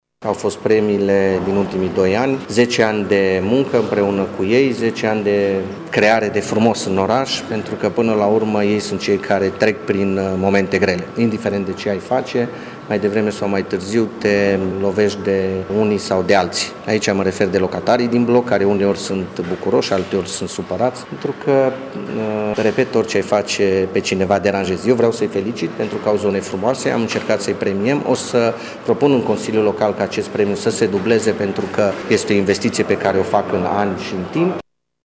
Dacă la ediţia din 2013 au fost 24 de asociaţii de proprietari premiate, în anul 2014 numărul acestora a scăzut la 7, a declarat viceprimarul municipiului Tg.Mureș, Claudiu Maior.